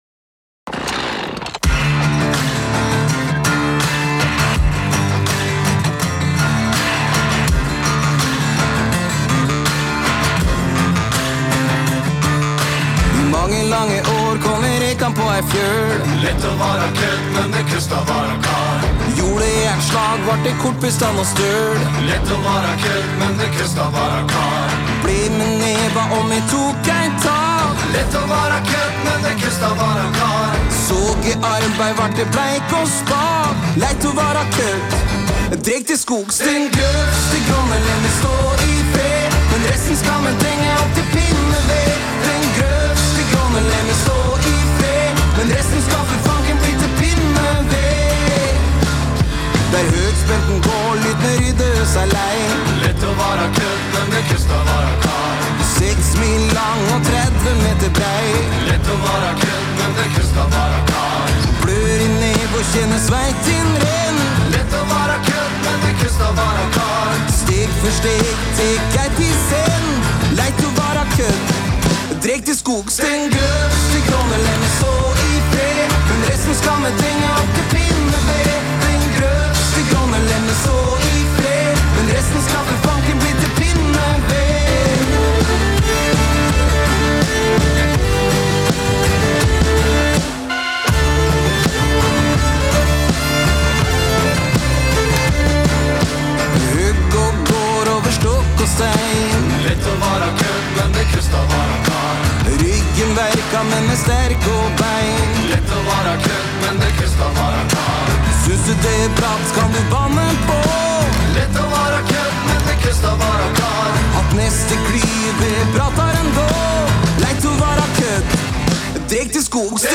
( Gledeshuset ) Hør gutta fortelle fra ide til vel gjennomført første premiere. Vi snakker også om andre forestillinger, og behovet for kultur i distriktet. Sendingen kan også høres på Radio Hønefoss på nett eller DAB klokken 16.46 og 21.15. https